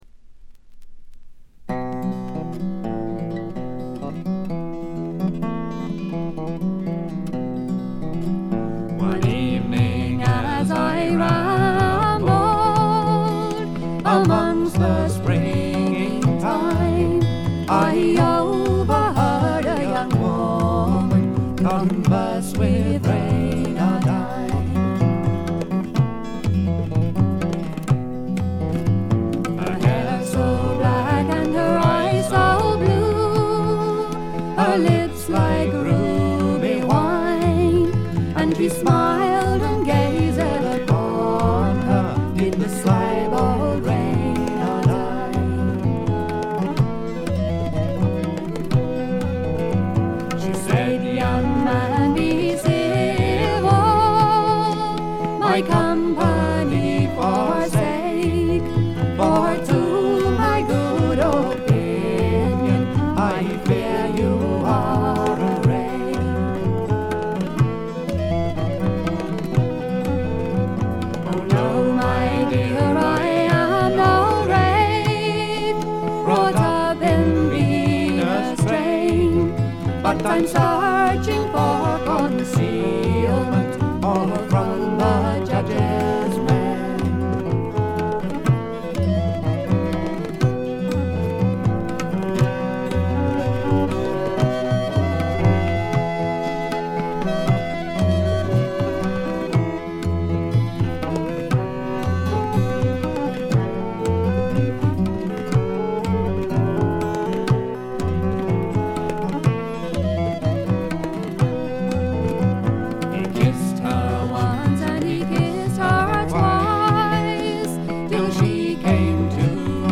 部分試聴ですがほとんどノイズ感無し。
また専任のタブラ奏者がいるのも驚きで、全編に鳴り響くタブラの音色が得も言われぬ独特の味わいを醸しだしています。
試聴曲は現品からの取り込み音源です。
vocals, flute, recorders, oboe, piccolo
fiddle, vocals
tabla, finger cymbals